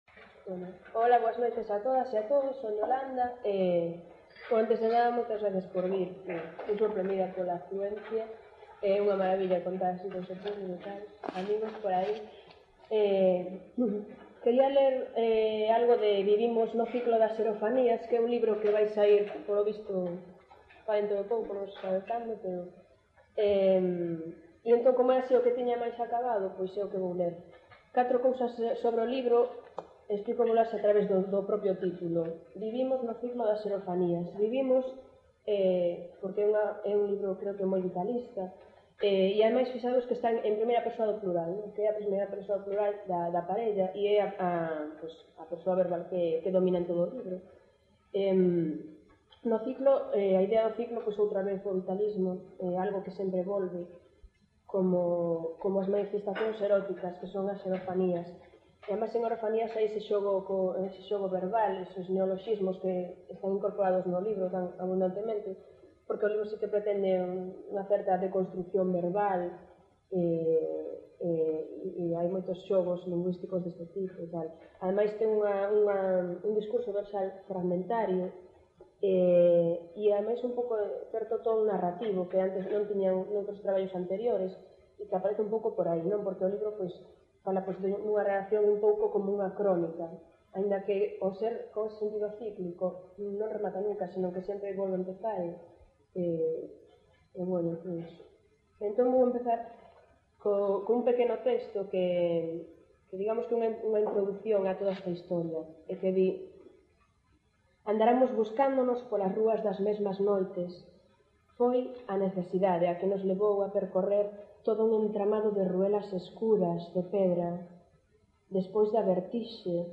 Dixitalizaci�n de audio: Reverso CCL.
Recital no Tarasca 17 minutos e 57 segundos ycastanho_1998_tarasca.mp3 (.mp3 16.4 Mb) Subcampos: 1976-2002 , Recital Descrición: Gravaci�n realizada no pub Tarasca de Santiago de Compostela (r�a Entremuros, 13) o 28 de agosto de 1998.